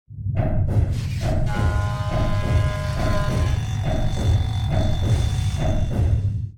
repair3.ogg